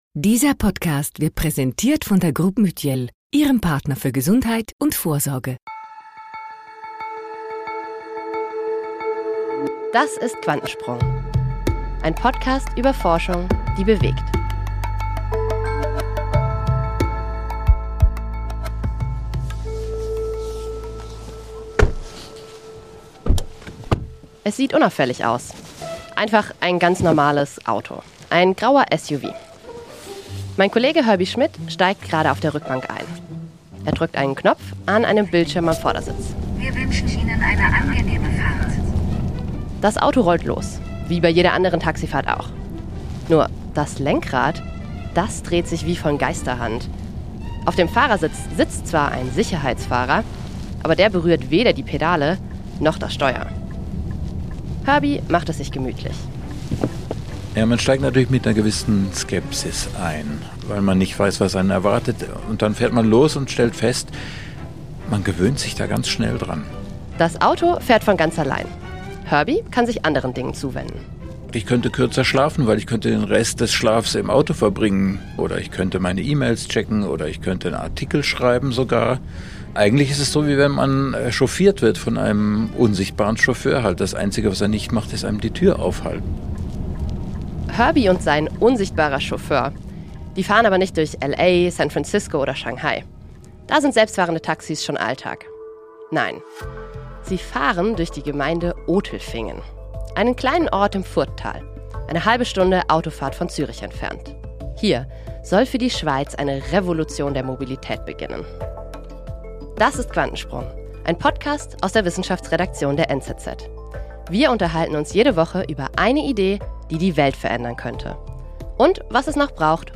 In dieser Folge von «NZZ Quantensprung» fahren wir im selbstfahrenden Taxi durch das Furttal bei Zürich – dort, wo die Schweiz ihre ersten praktischen Erfahrungen mit Robotaxis sammelt. Wie sicher fahren autonome Autos heute wirklich?